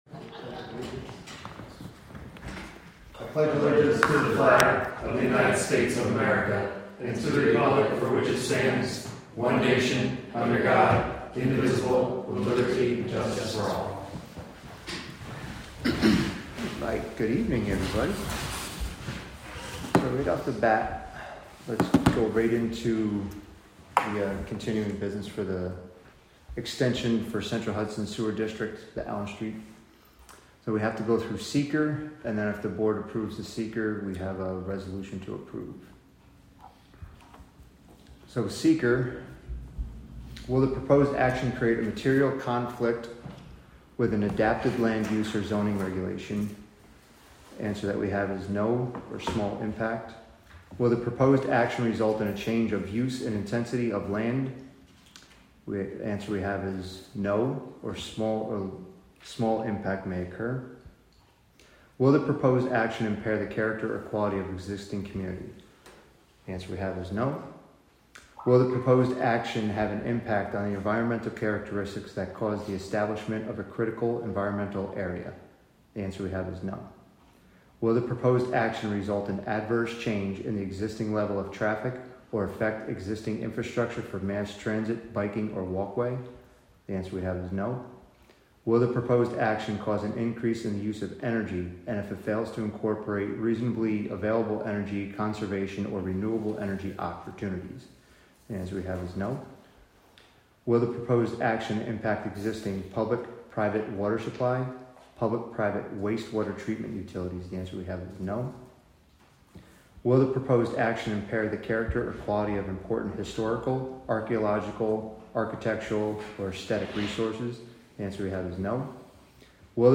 Live from the Town of Catskill: September 17, 2025 Catskill Town Board Committee Meeting (Audio)